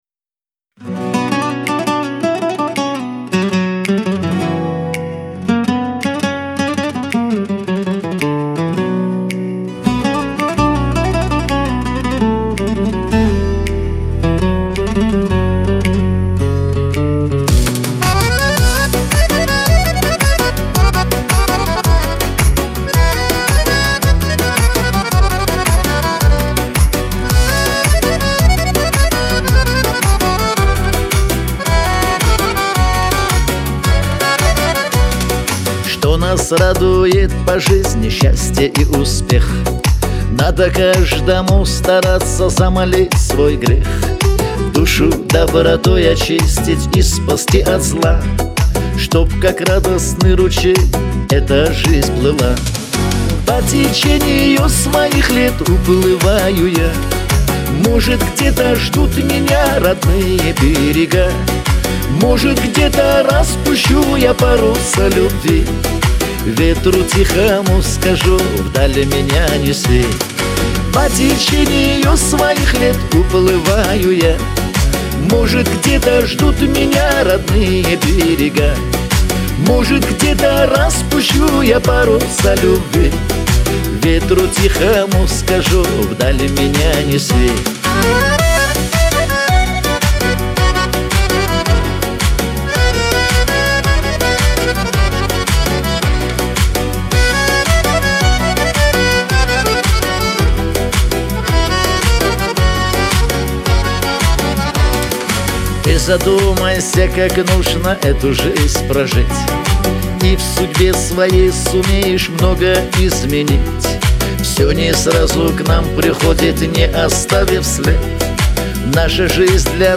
эстрада
pop